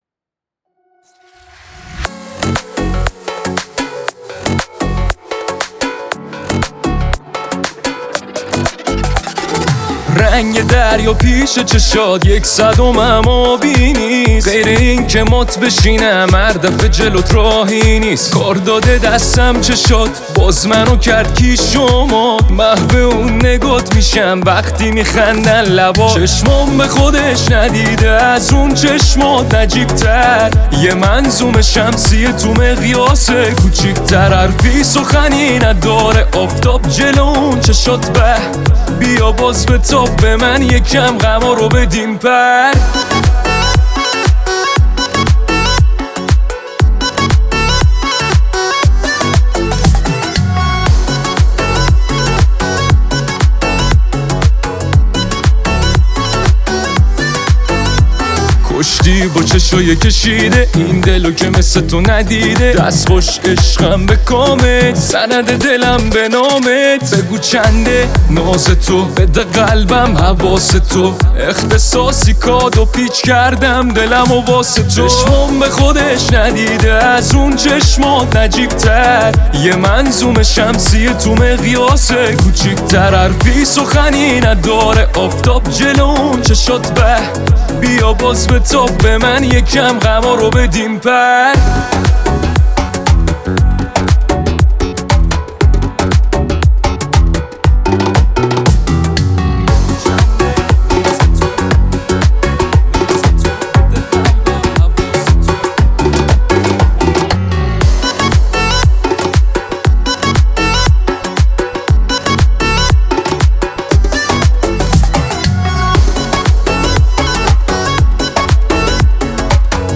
• آهنگ شاد